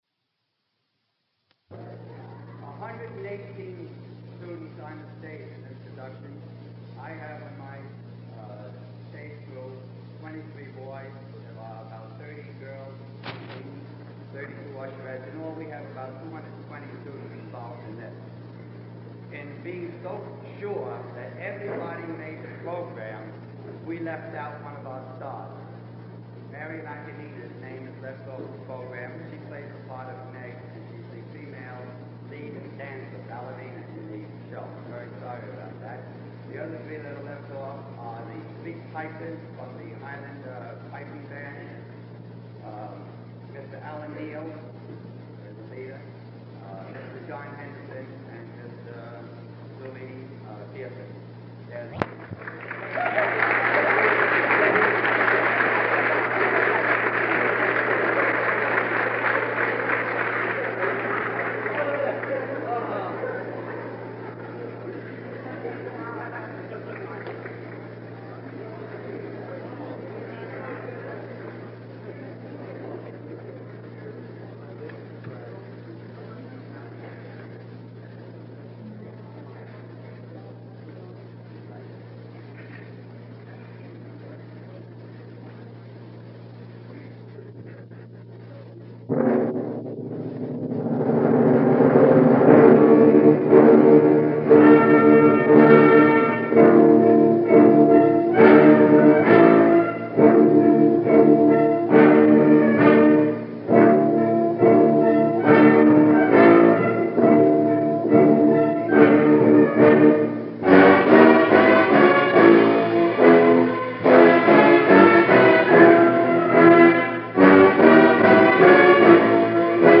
From a drawer somewhere, stored away for all those years on an old and nearly forgotten reel-to-reel tape in miraculously good condition, the ghost of Brigadoon has arisen to speak to us.
All musical selections include the surrounding dialogue.
overture